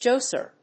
/dʒάsɚ(米国英語), dʒˈɔsə(英国英語)/